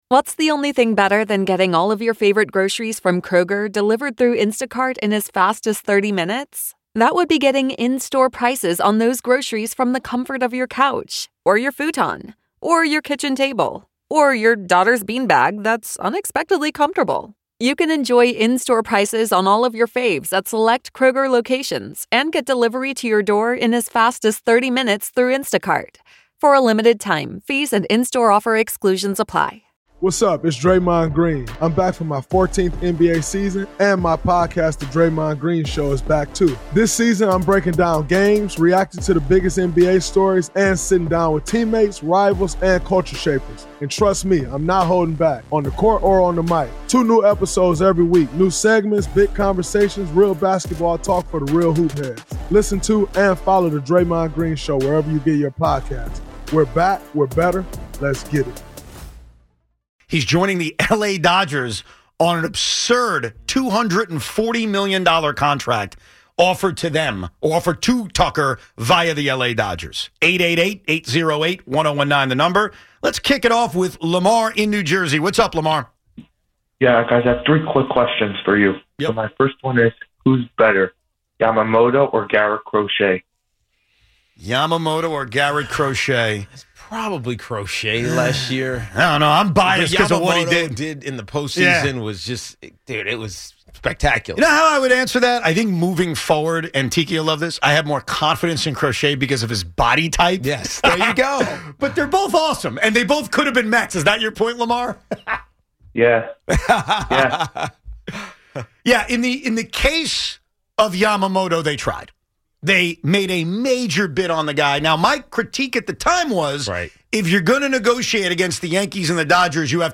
Kyle Tucker’s $240 million deal with the Dodgers sparks a loaded fan debate. Calls flood in on whether Yoshinobu Yamamoto or Garrett Crochet is the better arm, and why the Mets came up short on both.